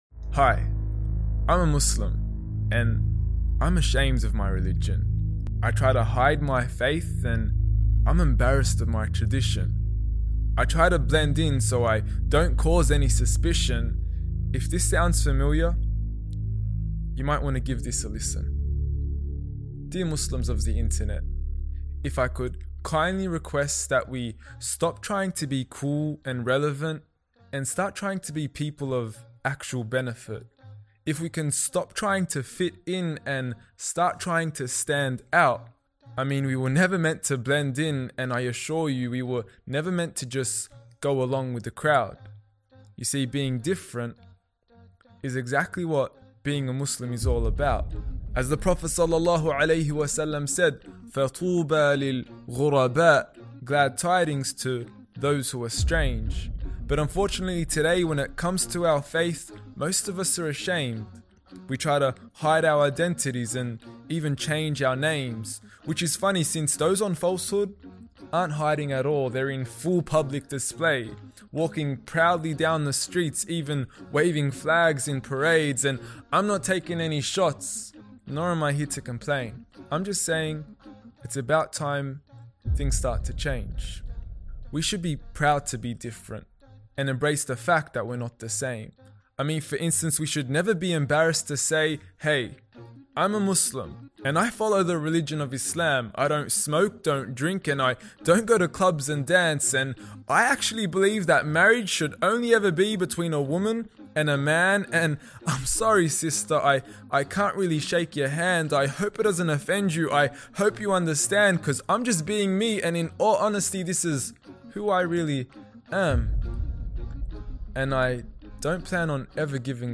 OnePath Network /Ashamed to be Muslim ｜ Spoken Word